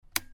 lightswitch.mp3